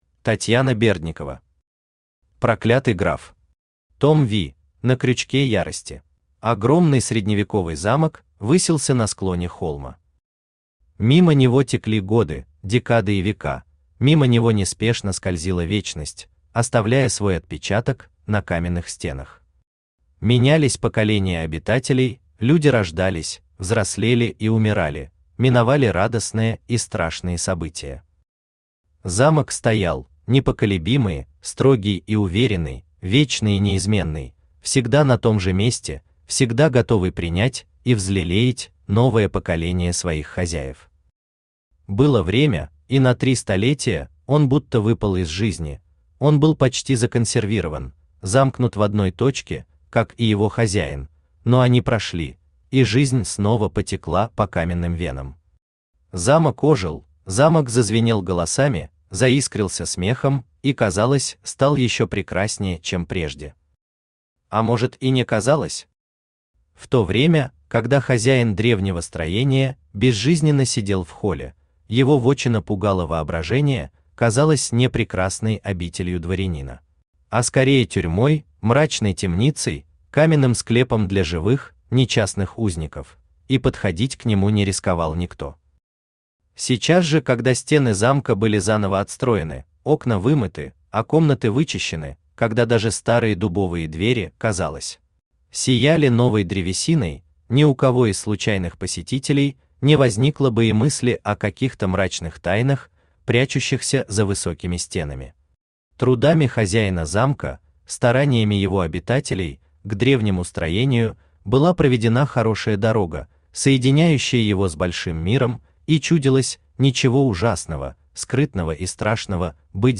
На крючке ярости Автор Татьяна Андреевна Бердникова Читает аудиокнигу Авточтец ЛитРес.